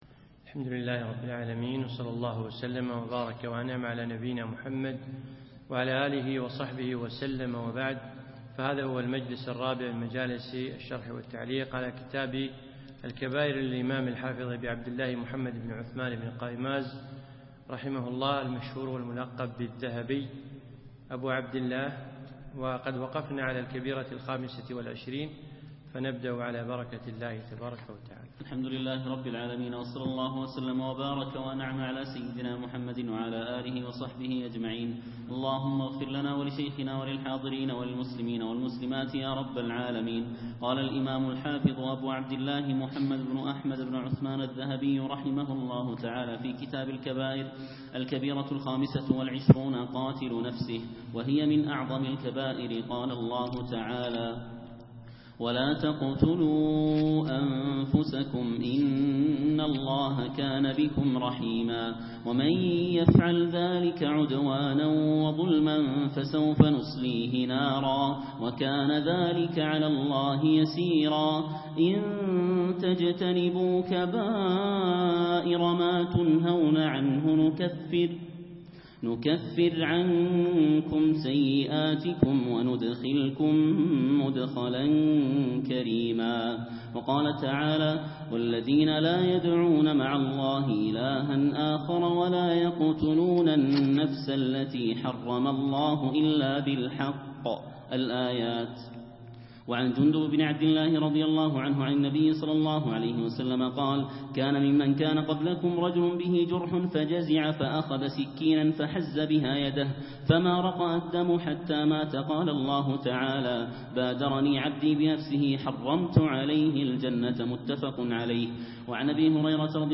يوم الأربعاء 18 ذو العقدة 1436هـ 2 9 2015م في مسجد عائشة المحري المسايل